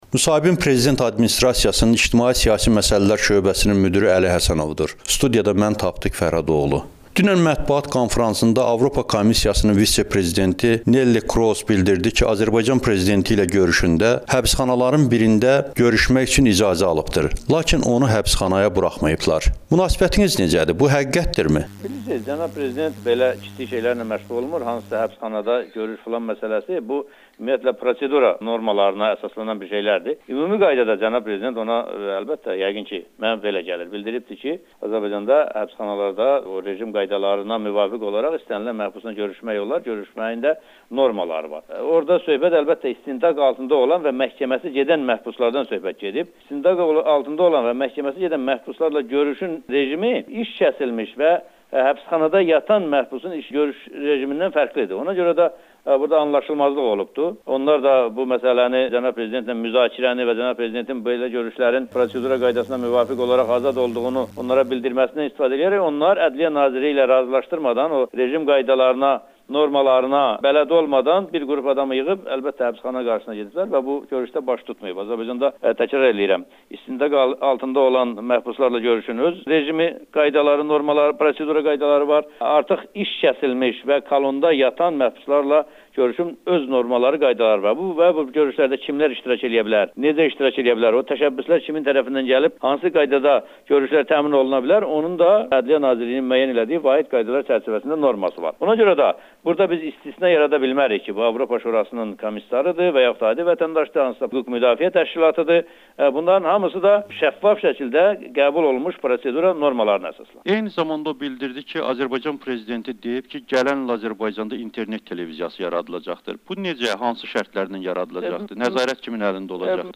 Əli Həsənovla müsahibə